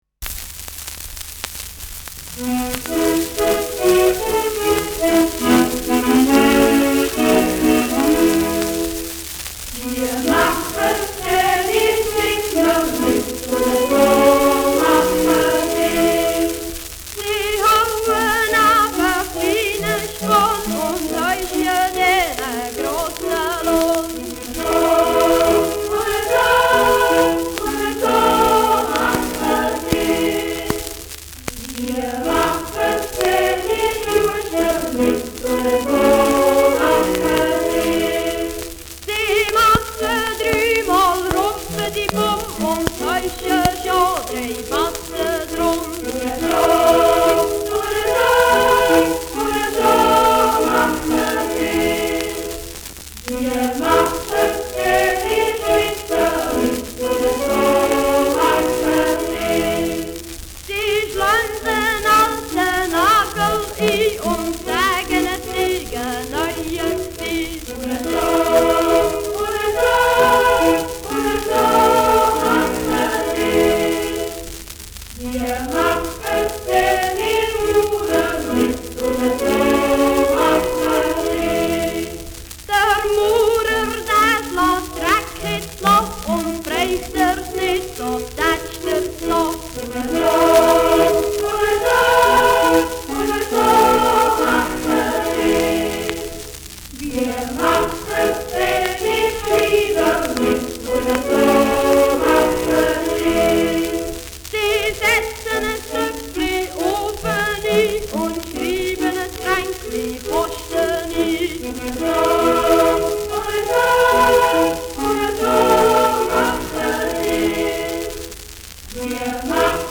Schellackplatte
präsentes Knistern : leichtes Rauschen
Berner Singbuben (Interpretation)
Spottlied auf verschiedene Handwerksberufe.